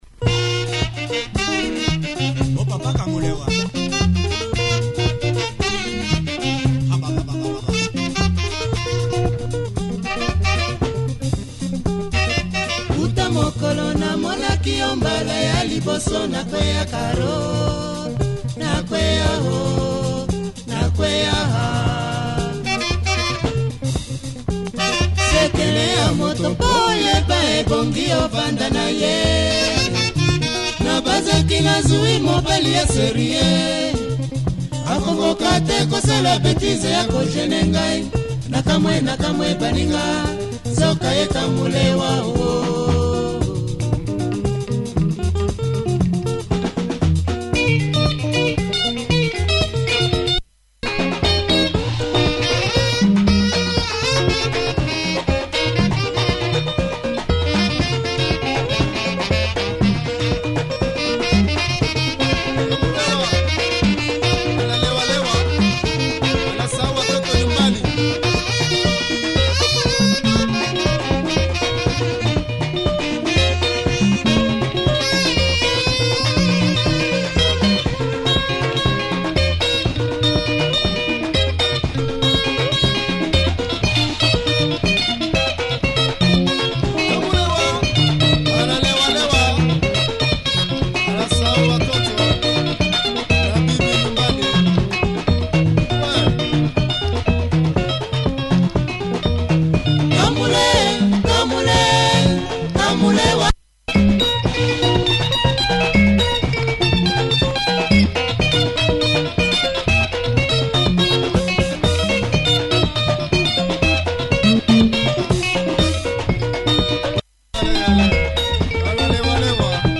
heavy breakdown on the flip